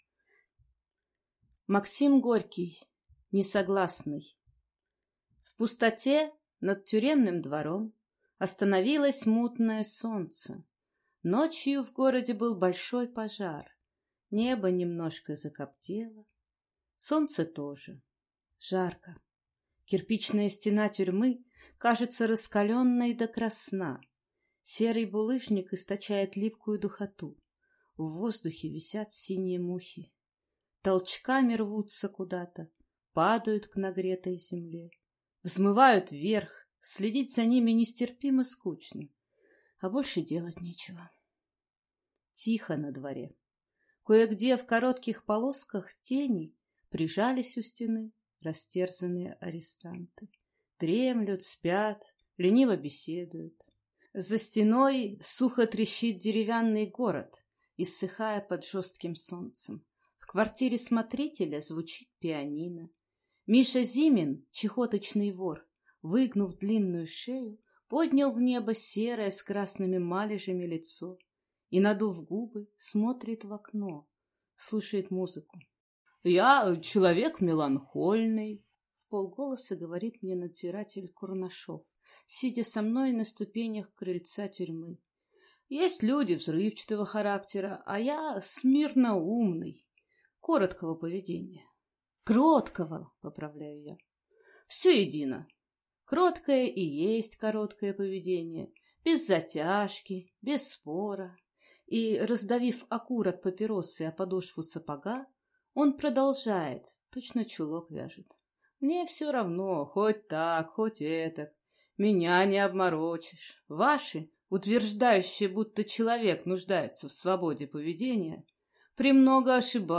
Аудиокнига «Тело знает все ответы. Как раскрыть себя и найти свое предназначение».